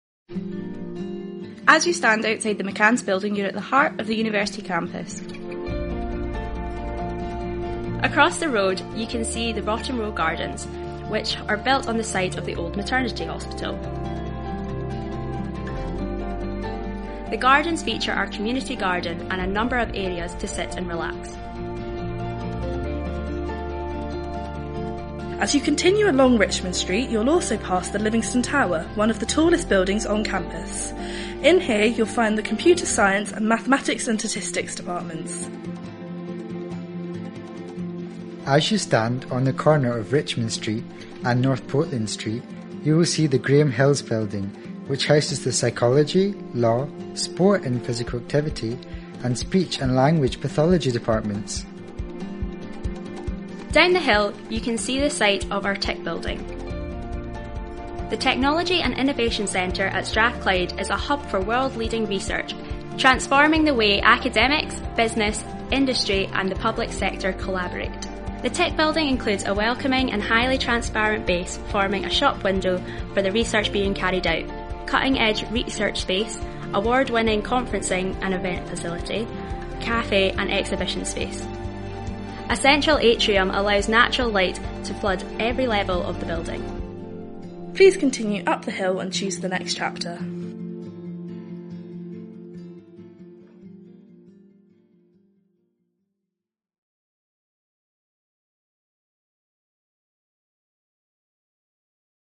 The tour is narrated by some of our current students studying here at the University of Strathclyde.